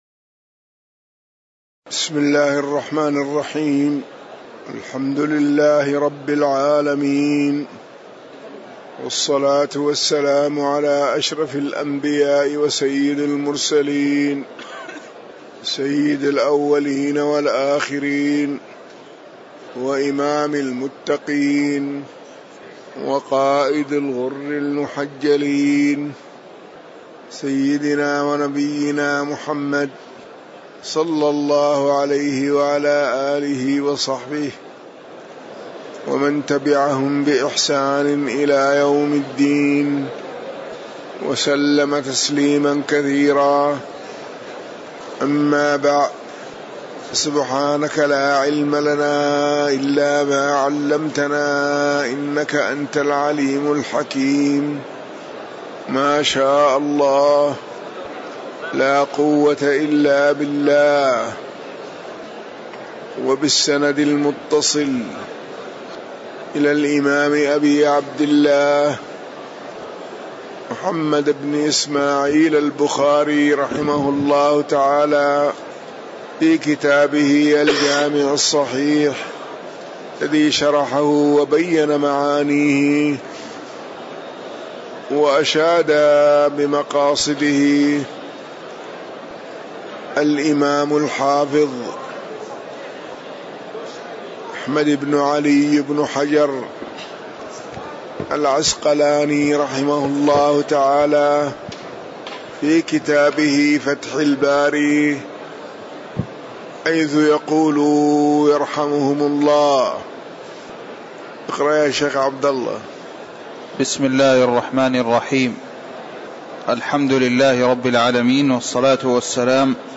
تاريخ النشر ١٩ ربيع الثاني ١٤٤١ هـ المكان: المسجد النبوي الشيخ